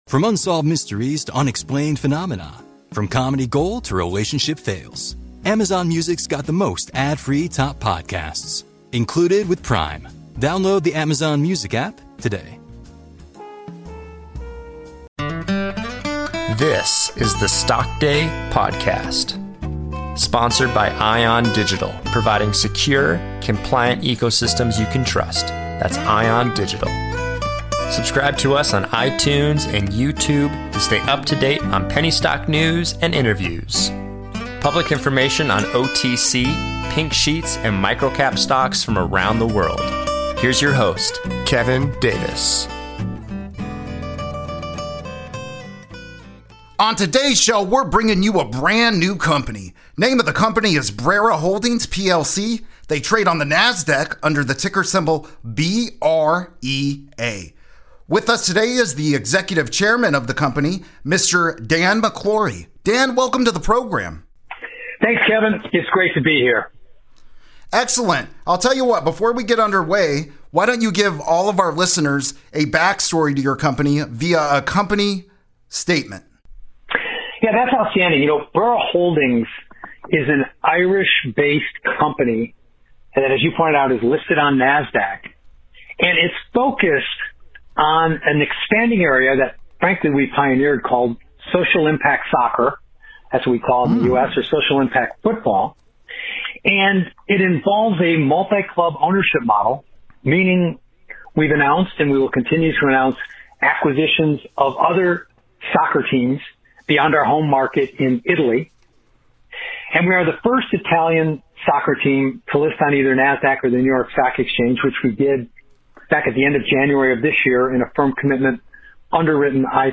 in a one-on-one session.